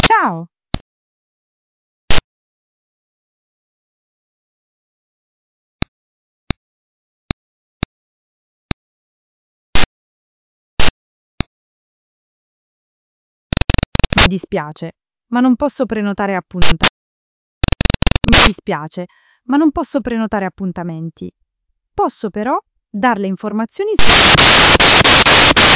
websocket Noise - VAPI
Hi Vapi Team ,I am experiencing a problem with VAPI's websocket as soon as audio comes in from Vapi's websocket to my Azure bridge, when Assistant speaks,there are background noises like spikes of clicks and pops, are you aware of this problem ?
In attachment you can find a Mp3 of the noise .